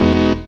HOUSE 4-L.wav